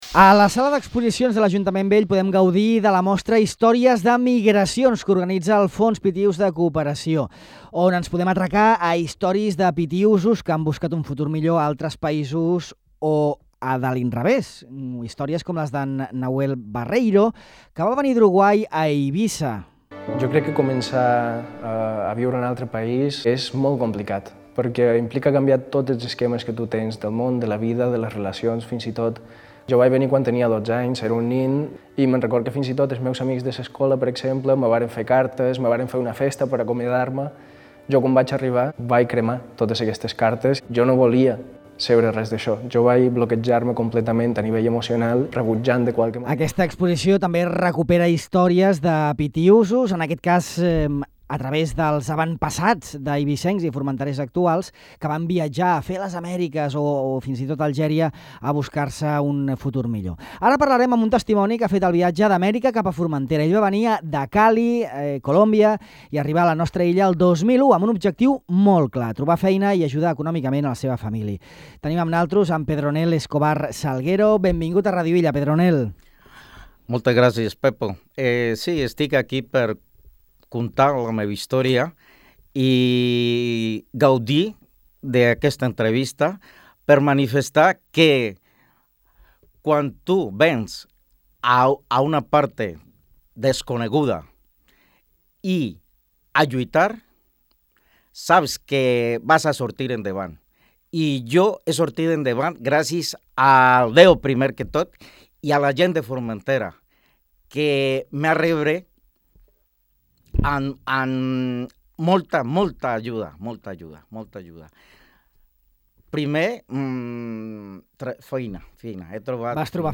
En aquesta entrevista a Ràdio Illa repassa els reptes que ha hagut de superar i com l’aprenentatge del català el va ajudar a integrar-se en la societat illenca.